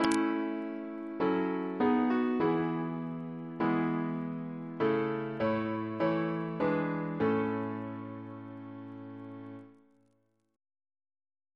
Single chant in A Composer: Thomas S. Dupuis (1733-1796), Organist and Composer to the Chapel Royal Reference psalters: PP/SNCB: 104